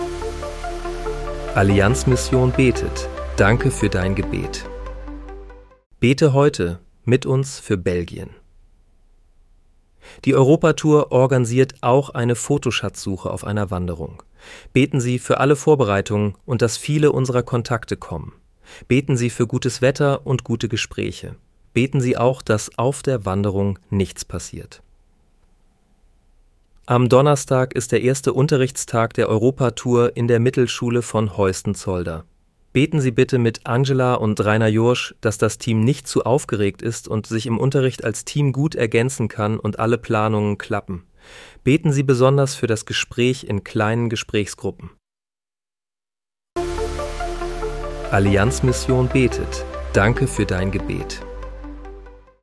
Bete am 05. März 2026 mit uns für Belgien. (KI-generiert mit der